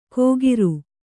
♪ kōgiru